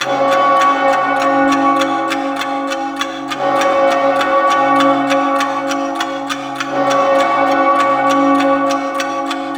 syssd_result_aaa_jingle.wav